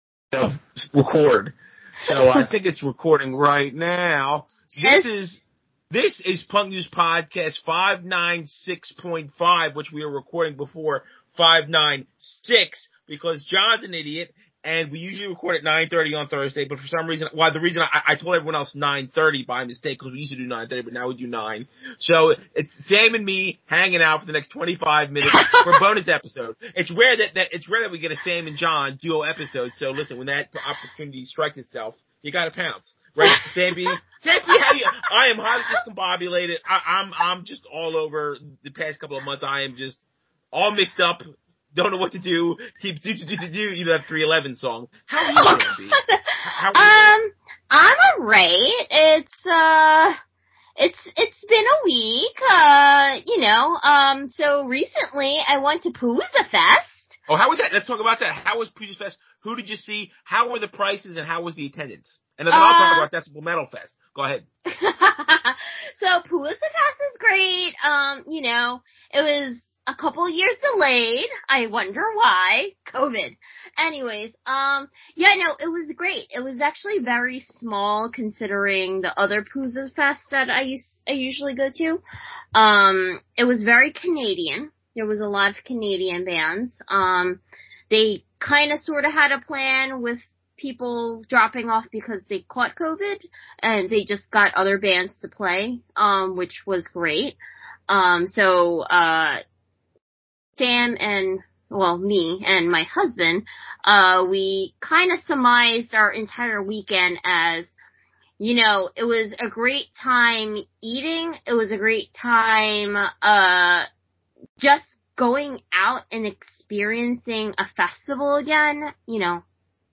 also, a special guest shows up halfway through...